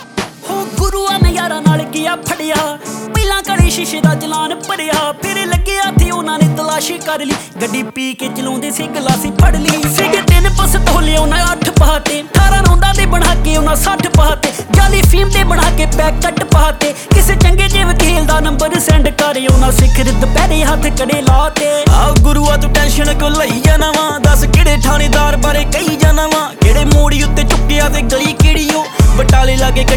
Punjabi Indian Regional Indian